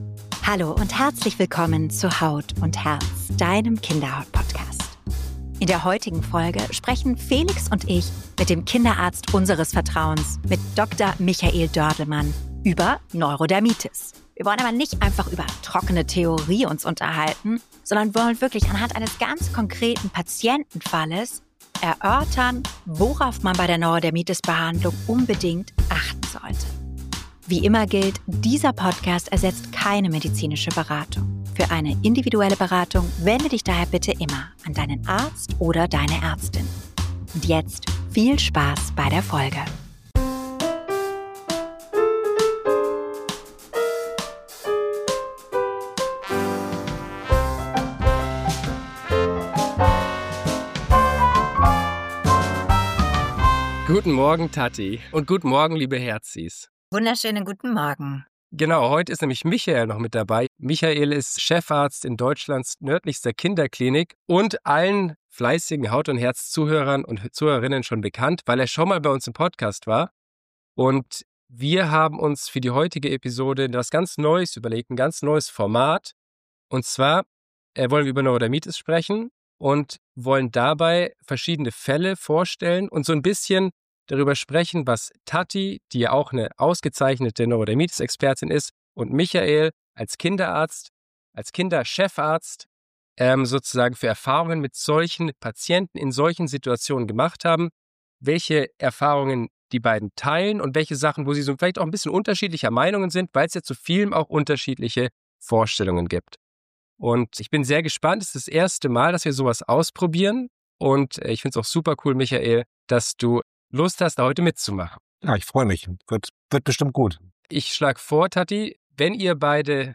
#92 NEURODERMITIS: Echte Fälle aus der Praxis - Experteninterview